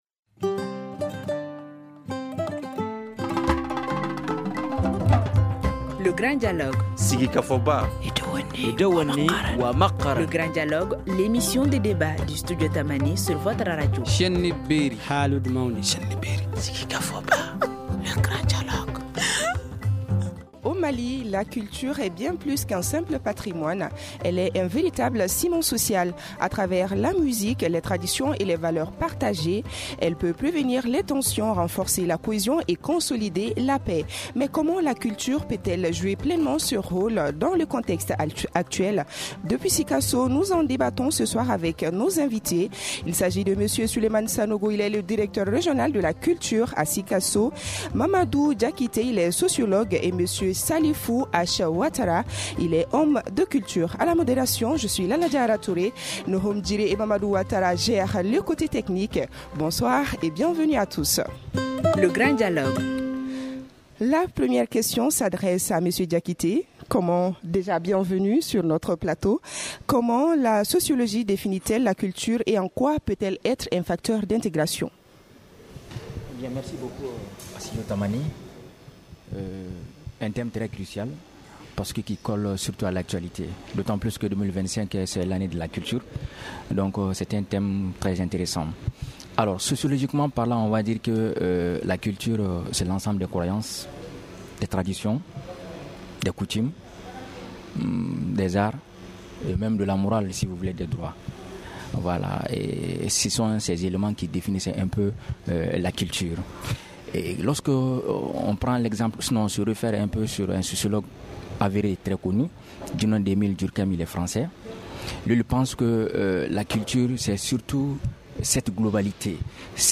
Et c’est depuis Sikasso, que nous allons débattre de tous ces aspects et pour cela nous recevons